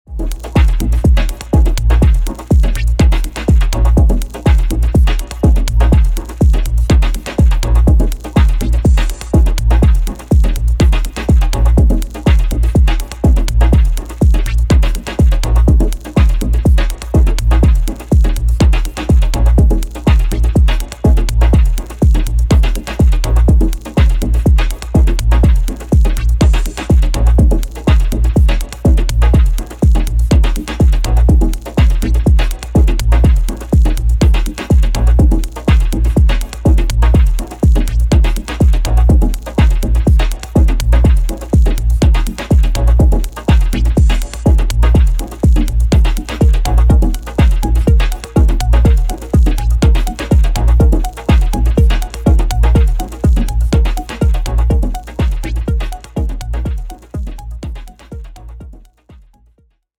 諸要素を絞り、音響のサイケデリックな効果に注力したミニマル・ハウスのモダンな最新形。